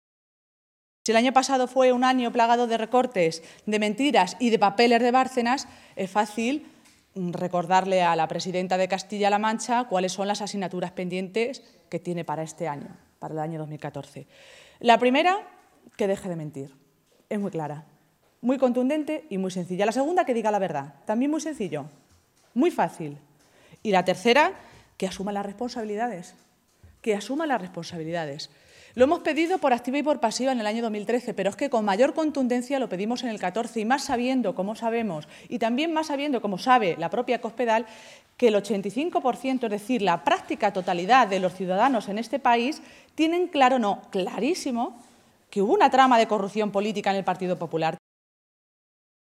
Maestre se pronunciaba de esta manera esta mañana, en Toledo, en una comparecencia ante los medios de comunicación en la que señalaba que el año 2013 recién finalizado fue, en Castilla-La Mancha, “un año plagado de recortes, de mentiras y de papeles de Bárcenas, y así empieza el año 2014”.
Cortes de audio de la rueda de prensa